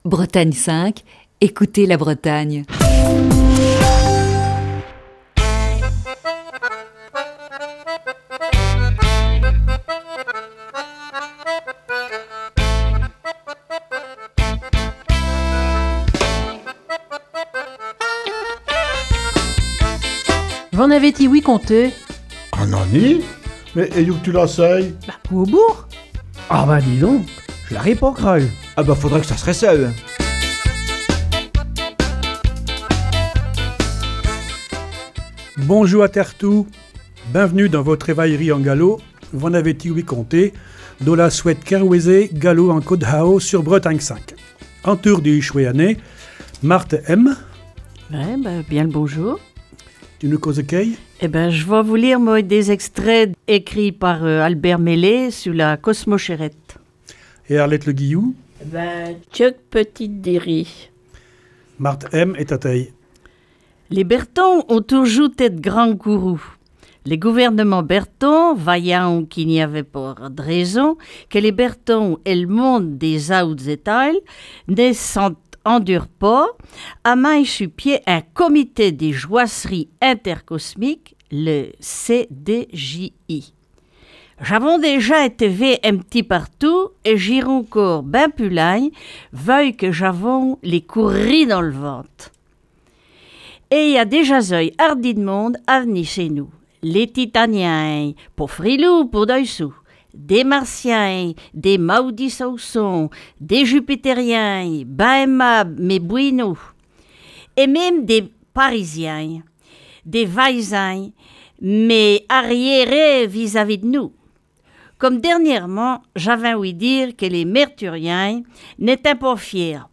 La troupe de V'en avez ti-ouï conté ? nous propose une série d'émissions basée sur "La Nuit de la Lecture", qui se déroulait le 18 janvier dernier à la Bibliothèque de Lamballe.